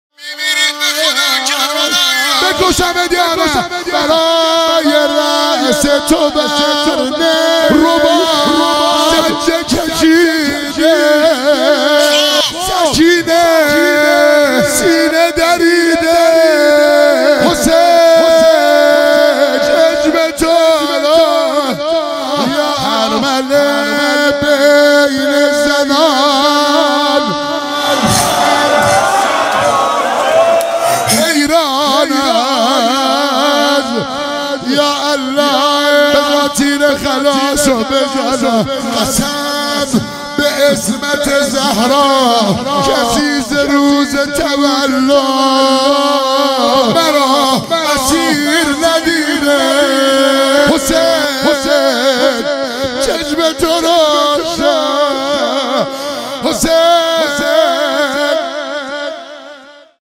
مداحی
هیئت جوانان سید الشهدا (ع) تهران